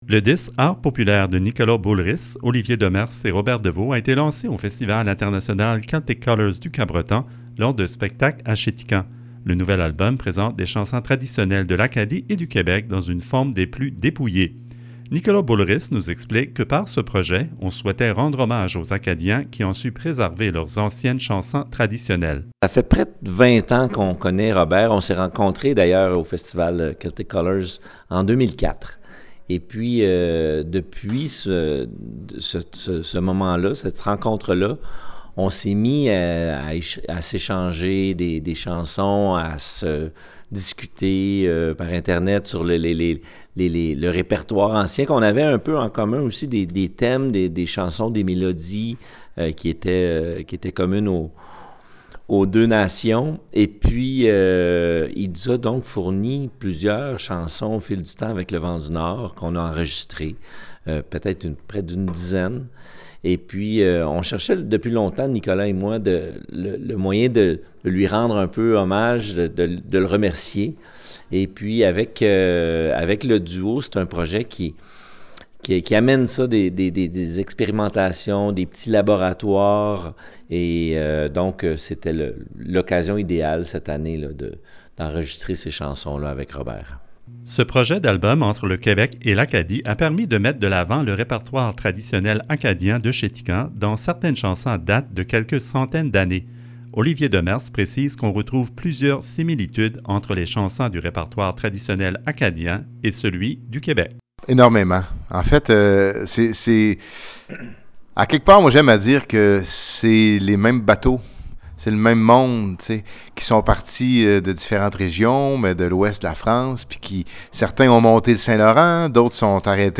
En entrevue, les artistes remarquent que le fond et la forme du nouvel album tournent autour du répertoire ancien des traditions orales francophones de l'Acadie et du Québec. Ils précisent que l'album présente des chansons du répertoire traditionnel acadien avant l'arrivée des pianos et des accordéons.